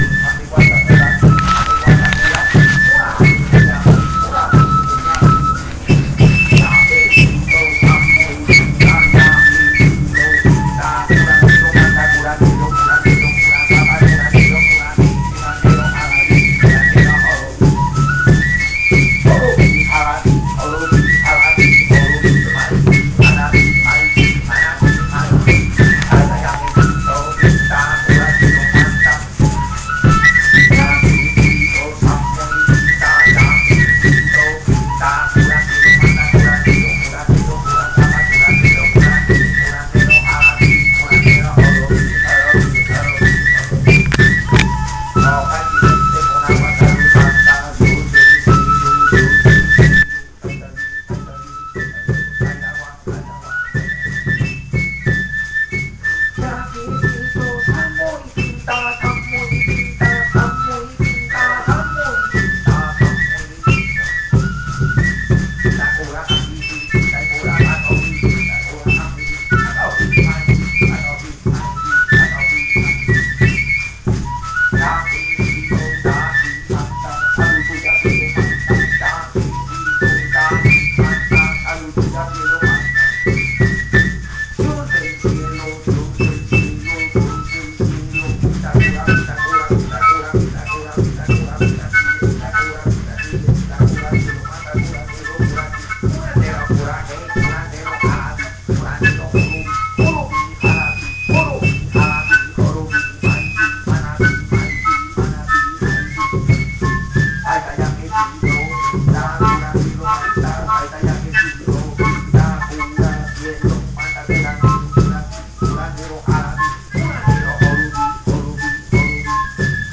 ceremonial song